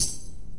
drum-slidertick.ogg